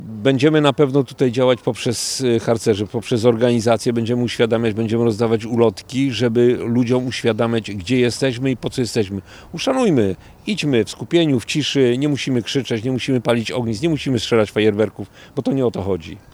Sleza-3-burmistrz.mp3